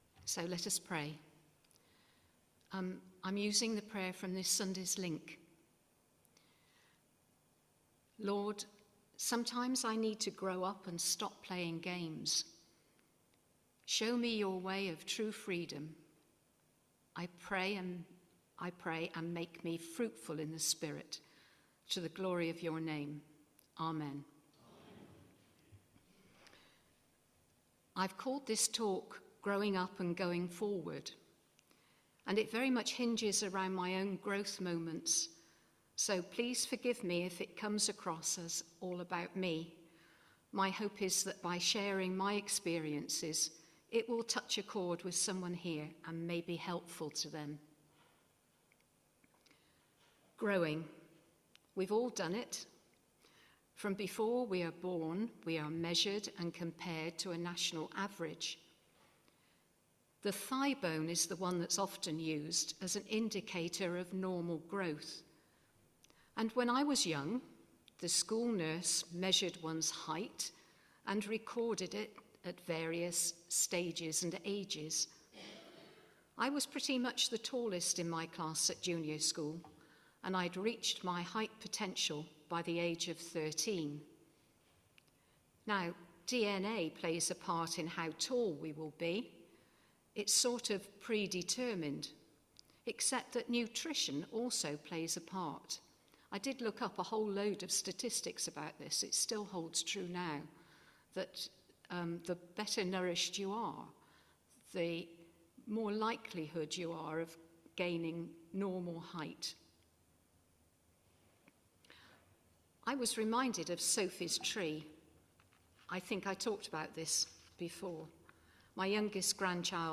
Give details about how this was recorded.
Media for Midweek Communion on Wed 02nd Jul 2025 10:00 Speaker